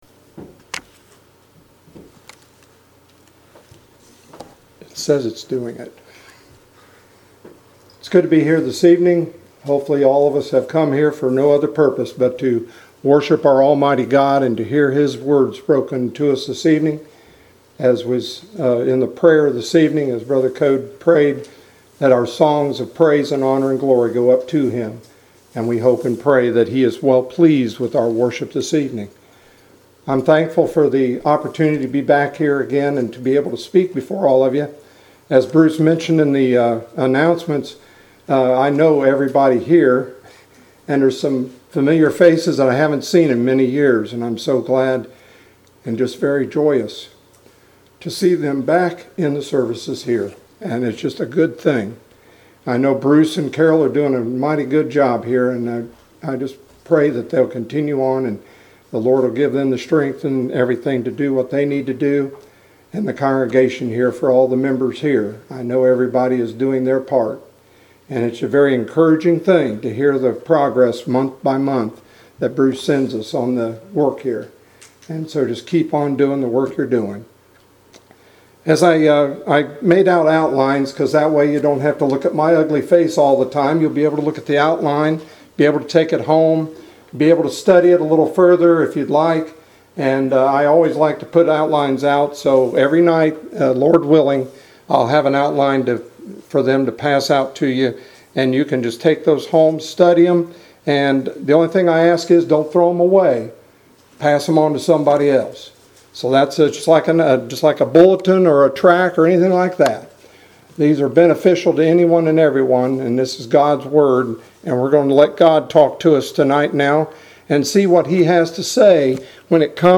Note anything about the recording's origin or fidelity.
2020 Fall Gospel Meeting Passage: Luke 10:25-37 Service Type: Gospel Meeting « Nahum.